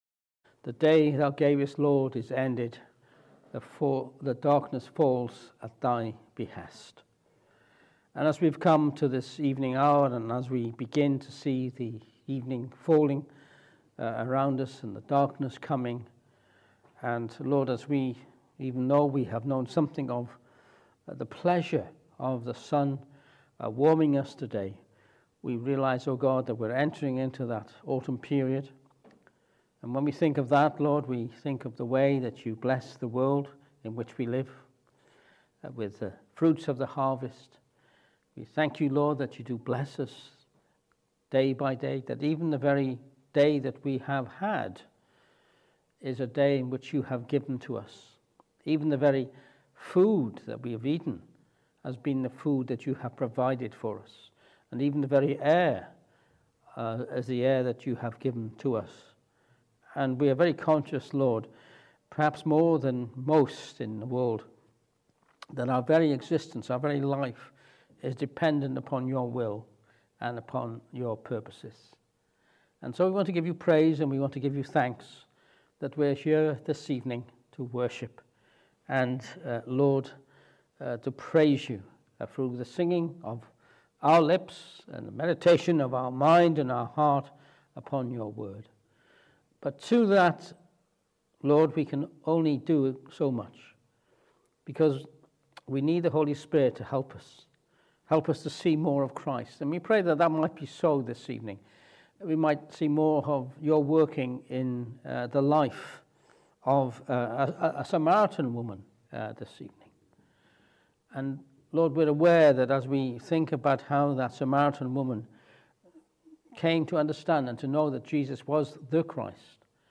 John 4:1-30 Service Type: Evening Service This evening we countinue our mini series on encounters with Jesus.